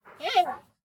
Minecraft Version Minecraft Version snapshot Latest Release | Latest Snapshot snapshot / assets / minecraft / sounds / mob / panda / idle2.ogg Compare With Compare With Latest Release | Latest Snapshot